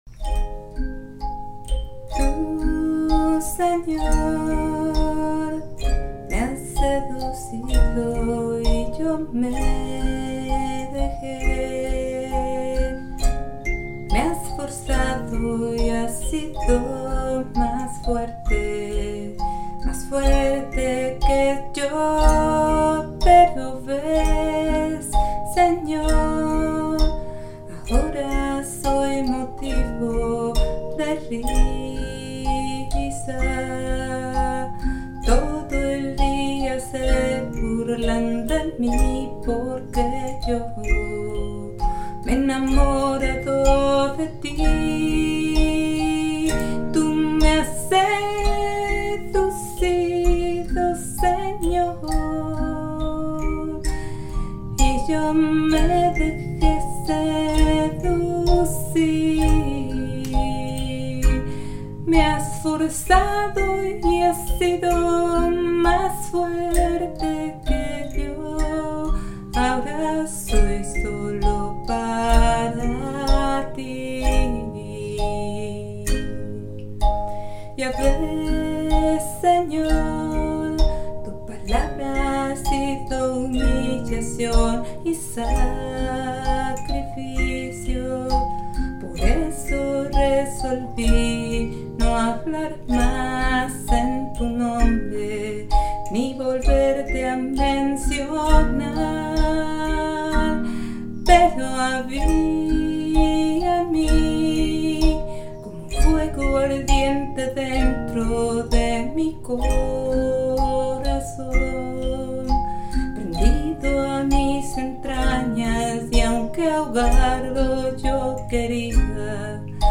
CANCION